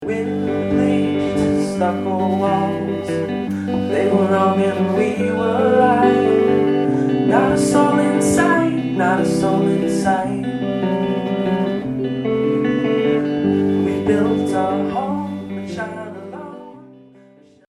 Live ate the Revolution Cafe